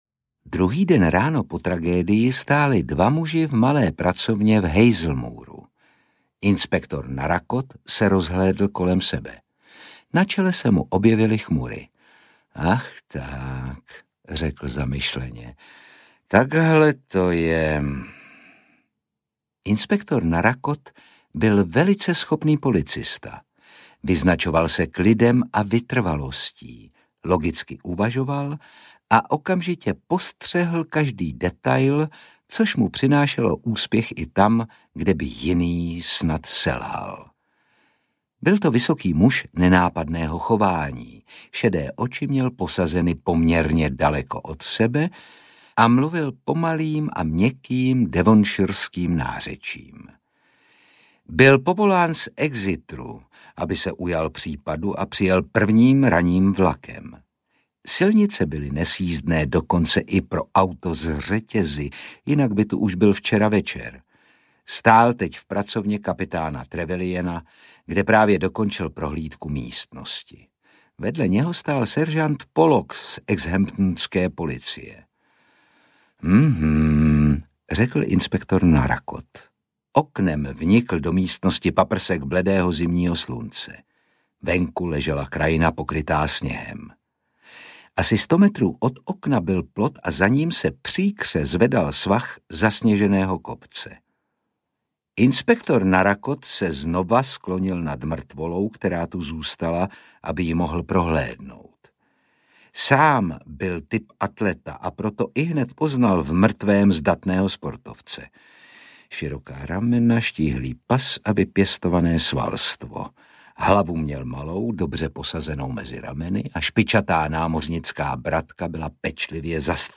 Ukázka z knihy
• InterpretOtakar Brousek ml.